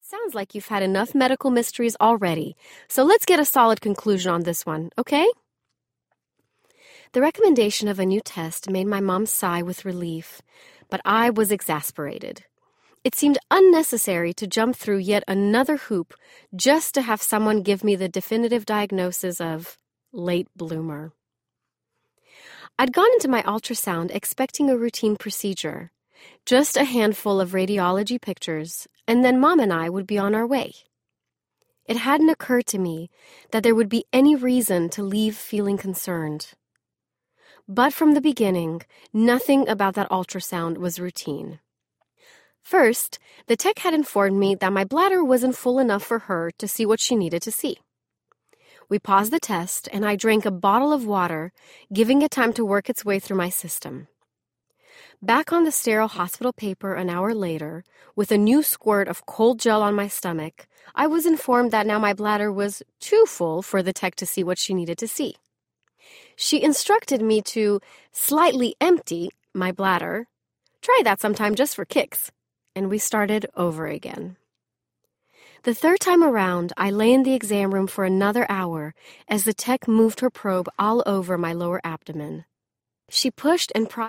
Audiobook
Narrator